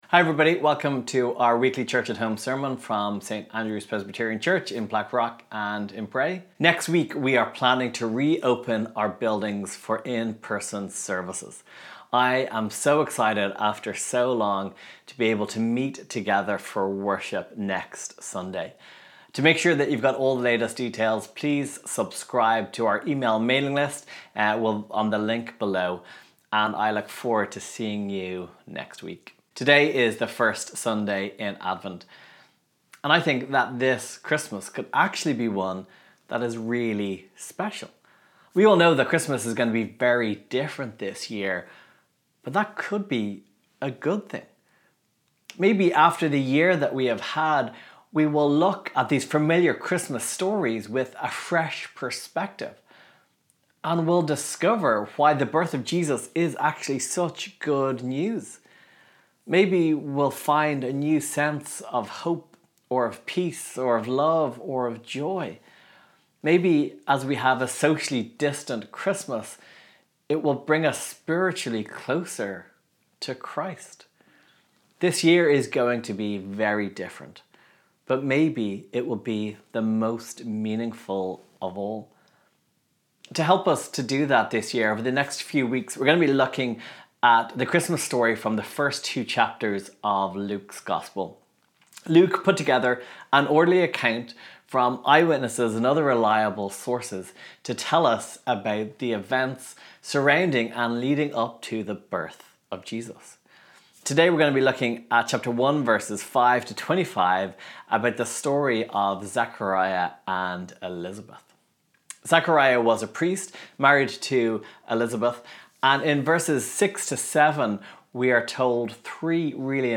Today is the first sermon in our new Advent series thinking about how we can have hope this Christmas.